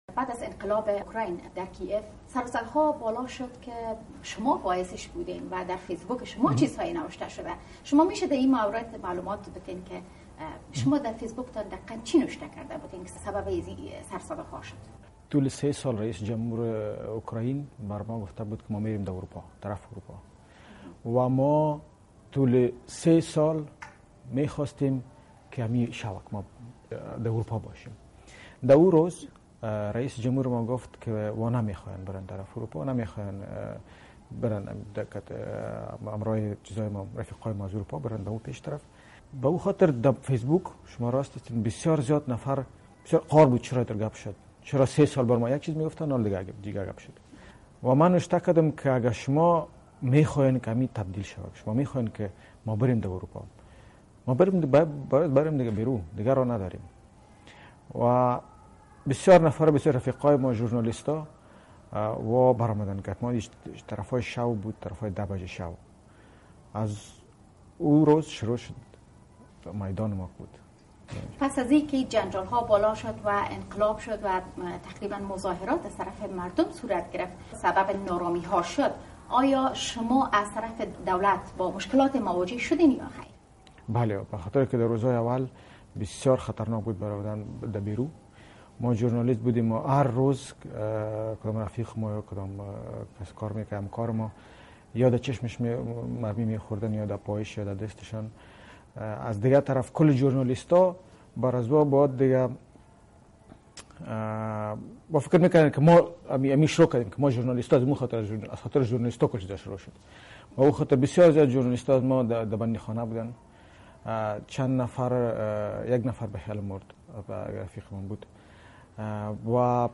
مصاحبه با یک عضو افغان تبار پارلمان اوکراین
مصاحبه ها